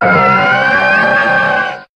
Cri de Milobellus dans Pokémon HOME.